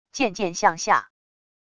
渐渐向下wav音频